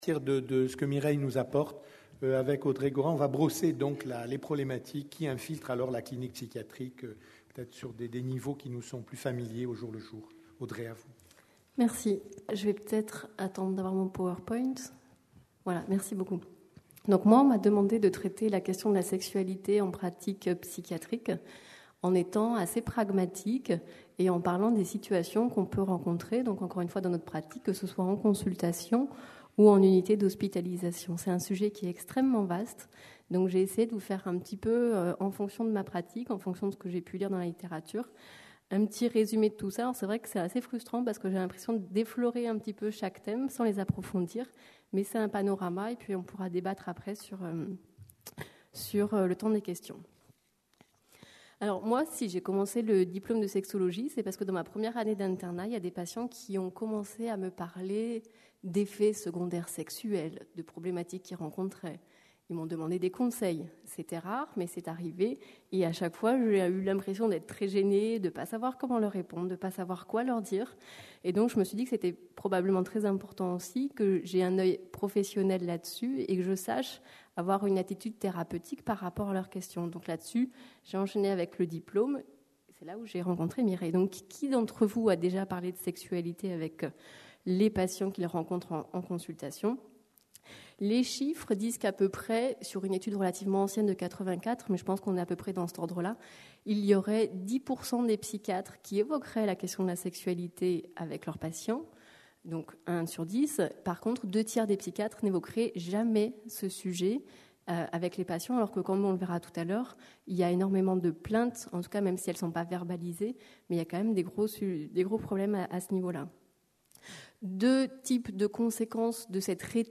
CNIPsy 2010 Marseille : 7ème Congrès National des Internes en Psychiatrie (CNIPsy).